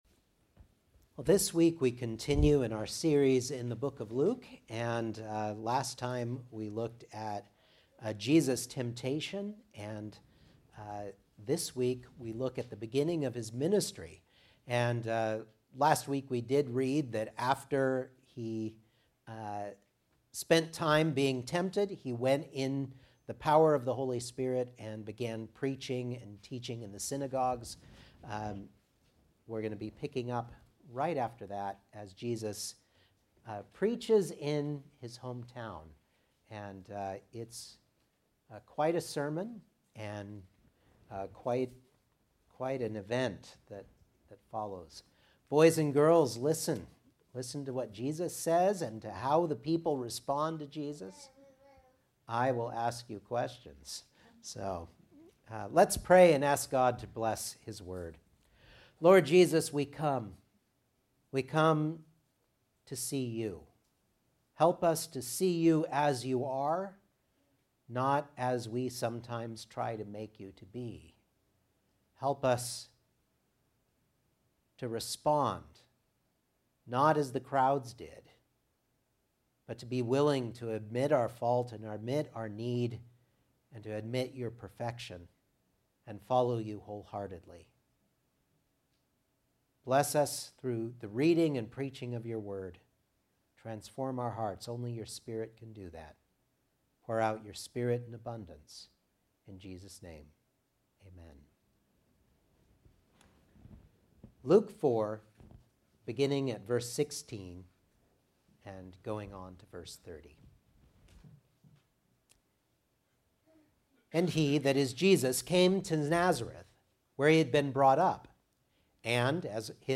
Luke 4:16-30 Service Type: Sunday Morning Outline